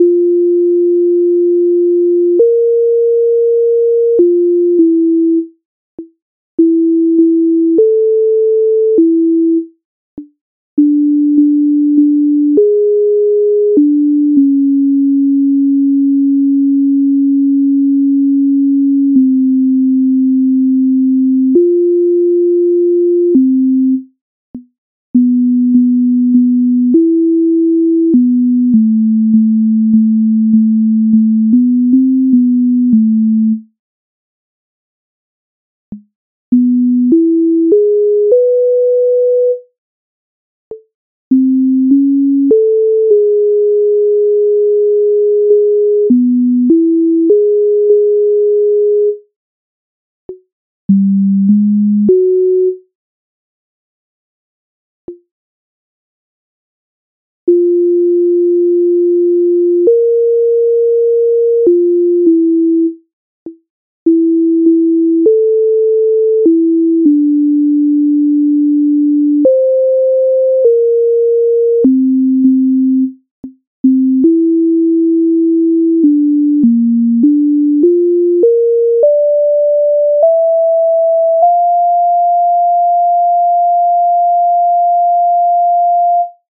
MIDI файл завантажено в тональності F-dur